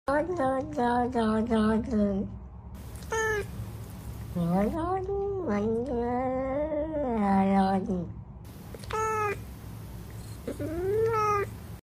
Cats Arguing Sound Effect Free Download
Cats Arguing